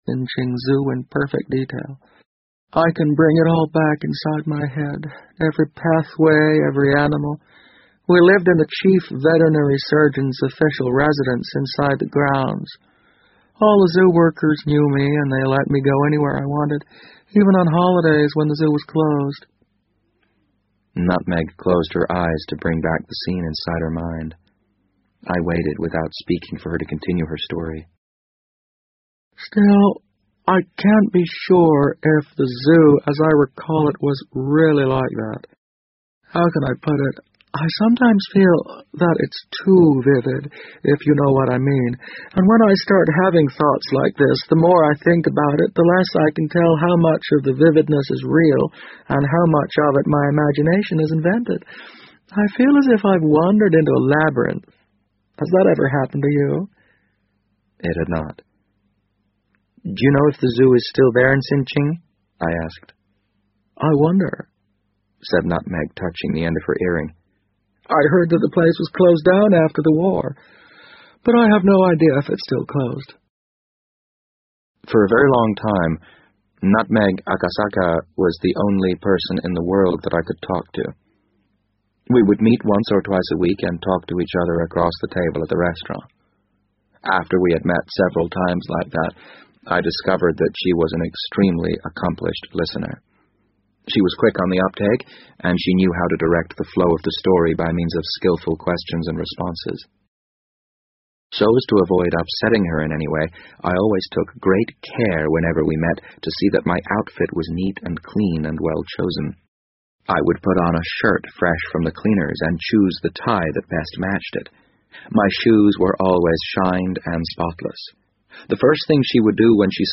BBC英文广播剧在线听 The Wind Up Bird 010 - 17 听力文件下载—在线英语听力室